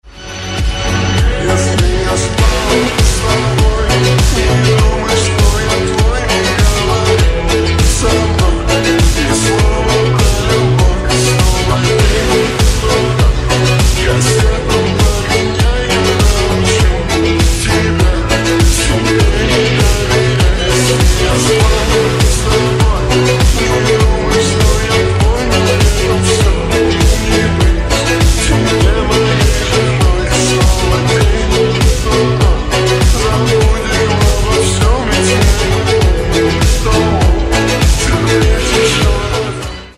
# Рингтоны Ремиксы
# Танцевальные Рингтоны